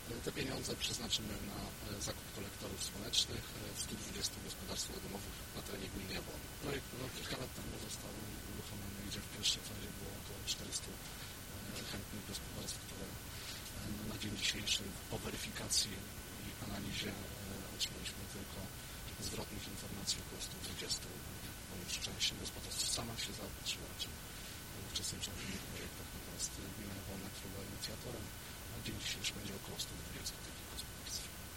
Wypowiedź: Wójt gminy Jabłonna Jarosław Chodorski - kolektory słoneczne